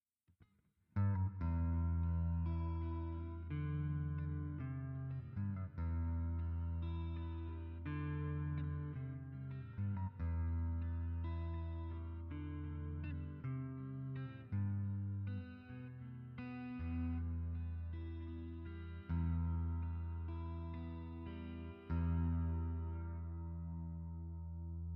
Factory-Preset